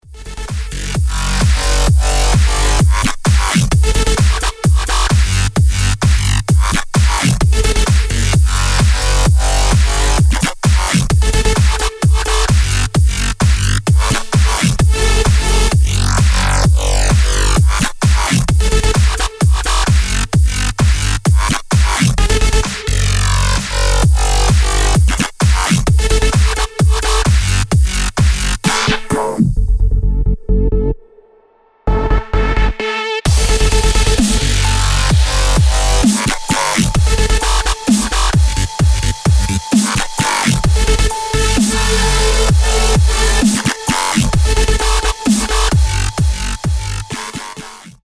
Клубные » Громкие » Без Слов » Электроника